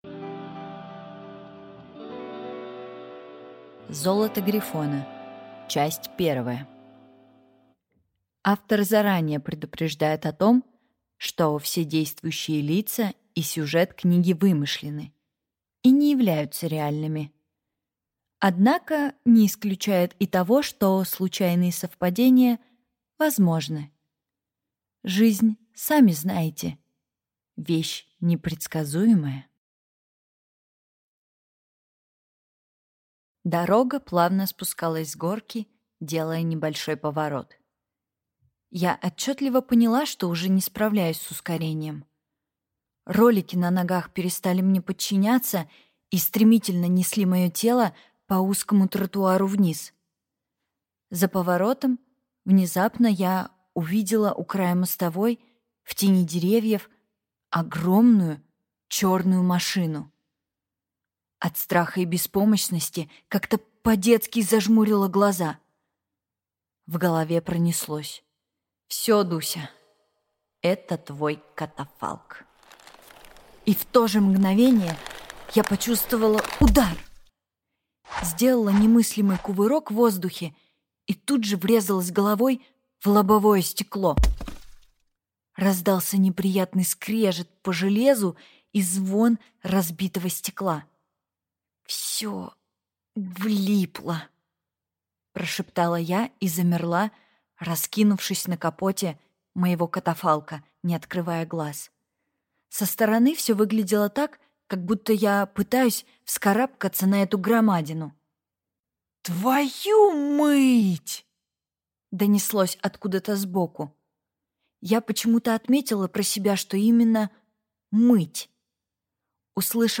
Аудиокнига Золото грифона | Библиотека аудиокниг